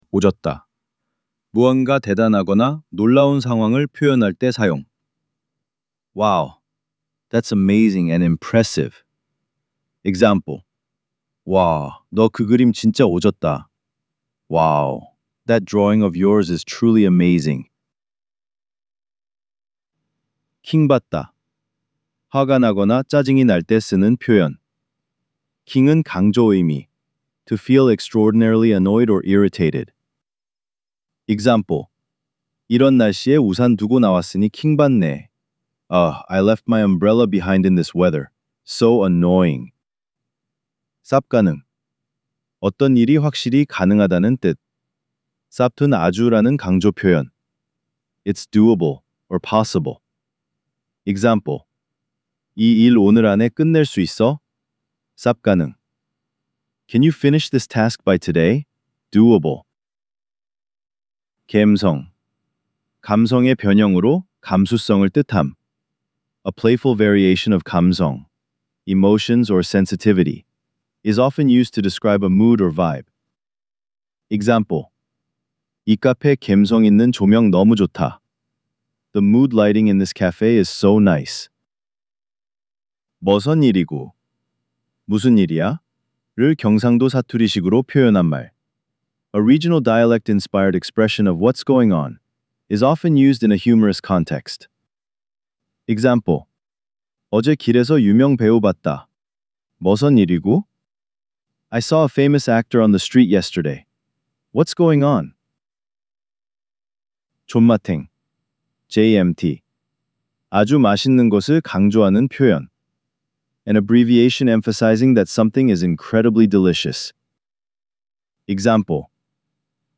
1) Korean Slang Decoded: Vol. 01 – Audio Narration